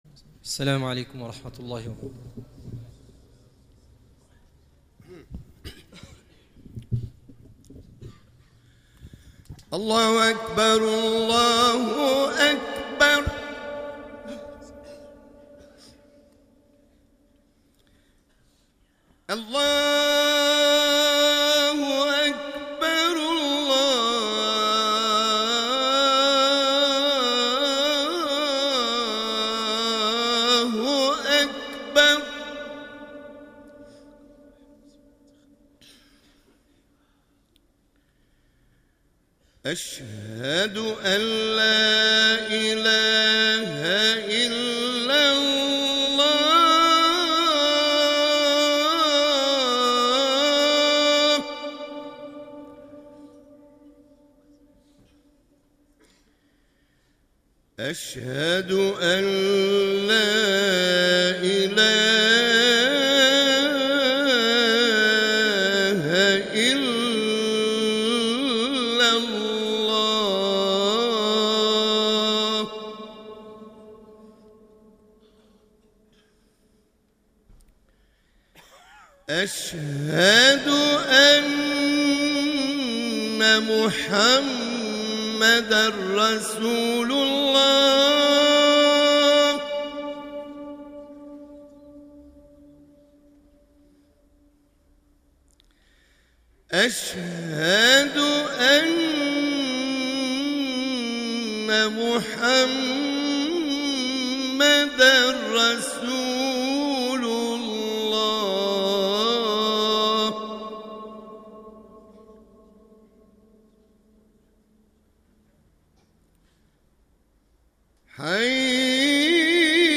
2014 Les prêches du Vendredi Télecharger le fichier MP3